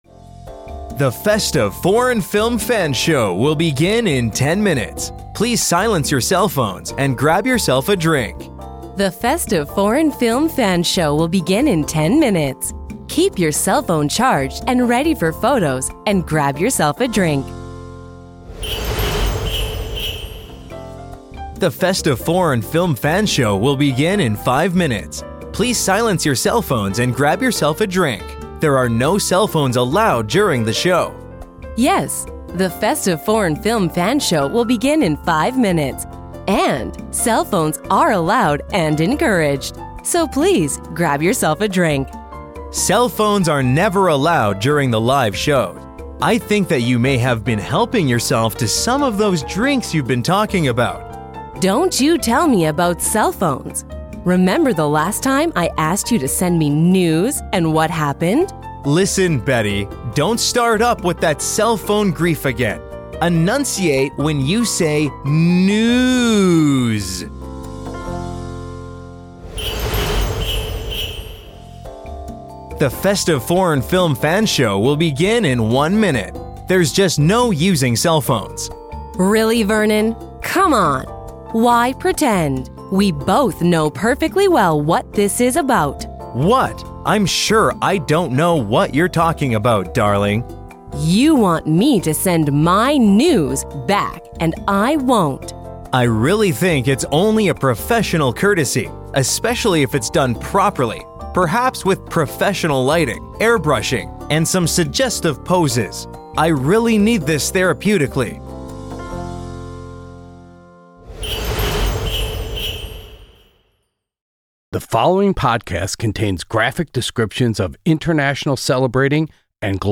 Now we are presenting our recent live Christmas event in audio form.
Since we can’t come over to your homes to pantomime the chaos of a live environment, we are going to inject some comments through the show to help you know what’s happening.
The Festive Foreign Film Fans Live Show unfolds in a nightclub where the air is buzzing with laughter and conversation.
Behind us are the Maeberries, a jazz band that swings through festive holiday standards.
Our Live Show is not like our podcast but more like a variety show.